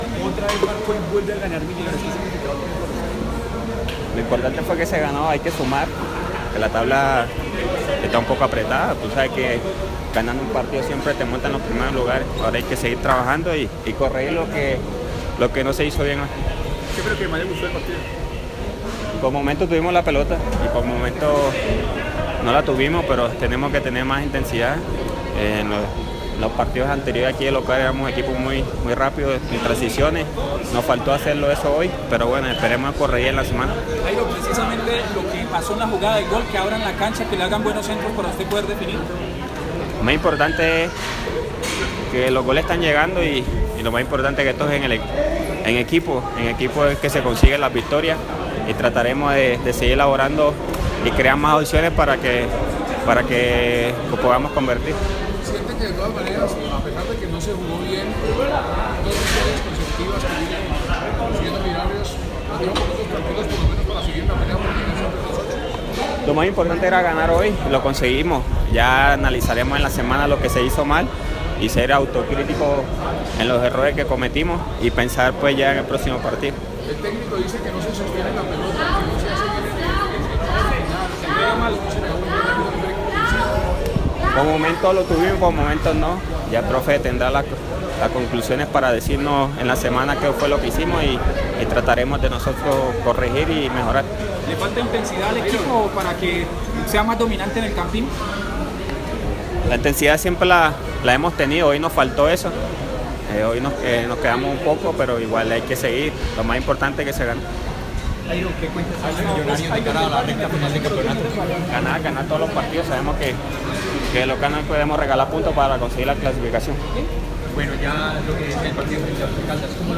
El delantero, que completó dos partidos seguidos con gol y llegó a cuatro anotaciones en 2017, habló con los medios luego de la victoria sobre el Huila.